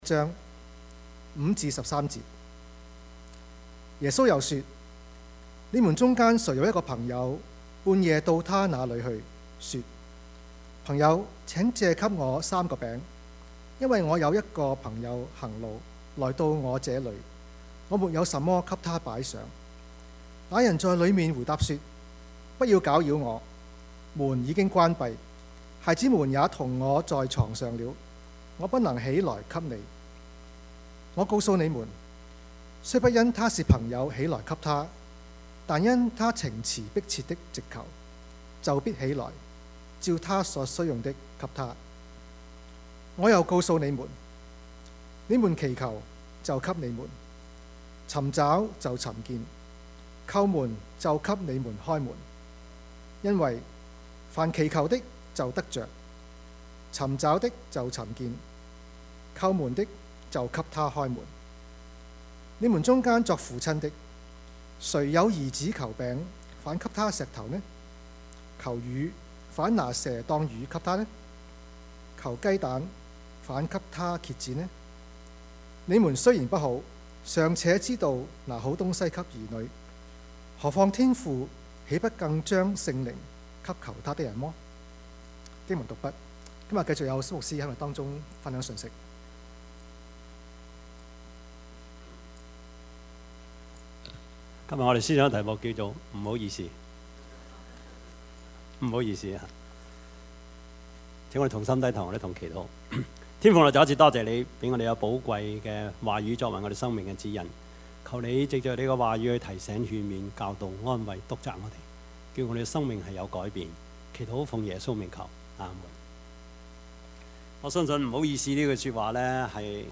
Passage: 路加福音十一：5-15 Service Type: 主日崇拜
Topics: 主日證道 « 門徒的禱告 魔鬼的愚民政策 »